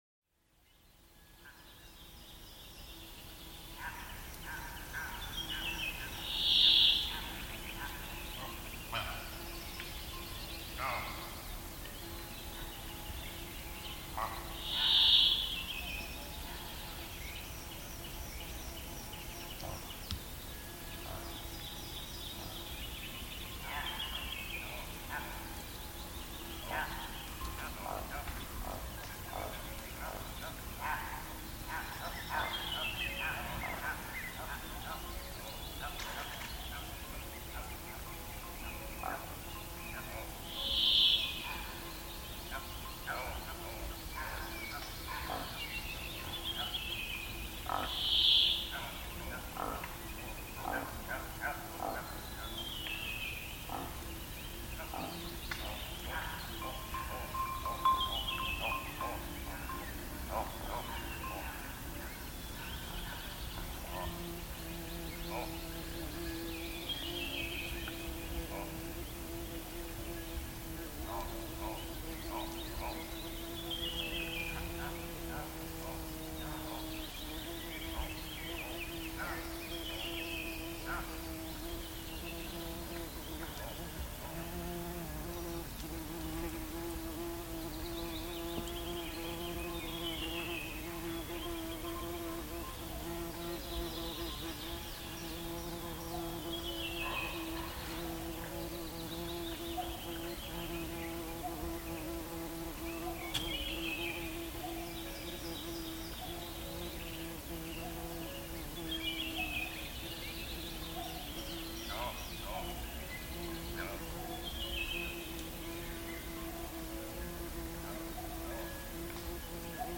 Nisäkkäiden ääniä, 5. osa: Poro
Poro.mp3